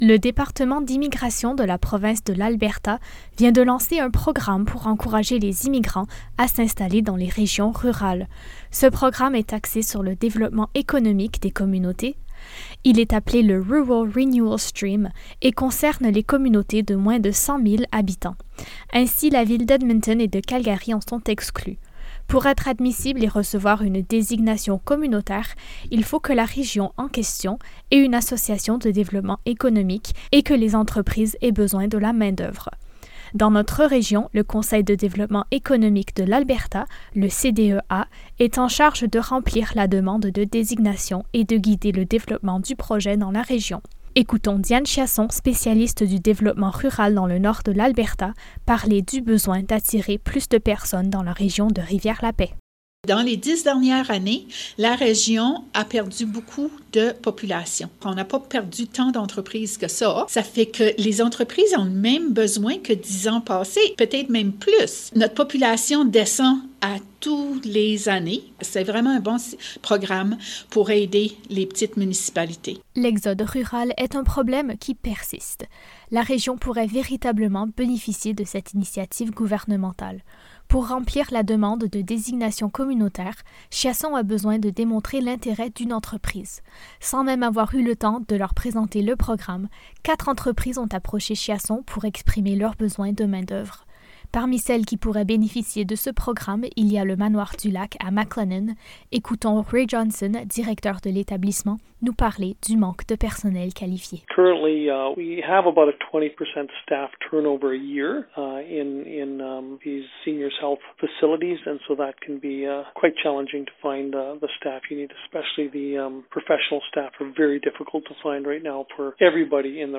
Écoutez le reportage pour en savoir davantage sur les avantages de cette initiative et la pénurie de main-d’œuvre dans la région de Rivière-la-Paix :